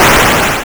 gunaddon.wav